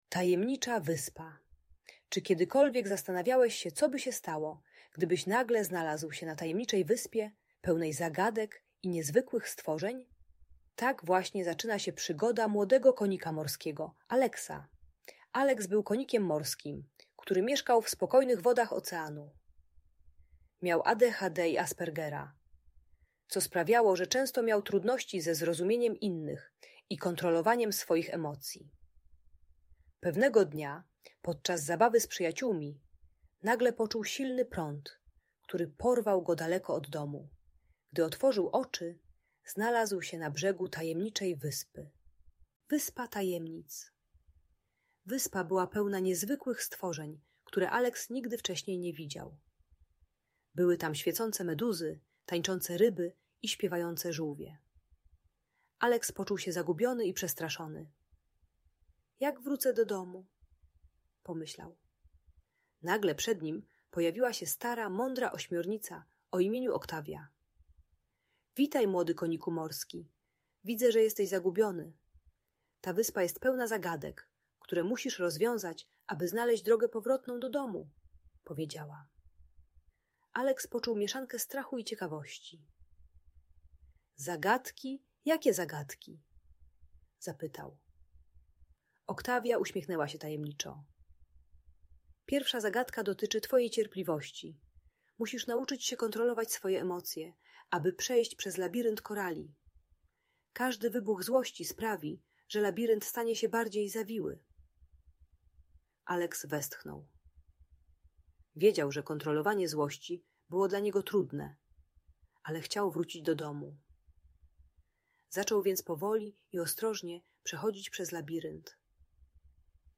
Tajemnicza Wyspa - Bunt i wybuchy złości | Audiobajka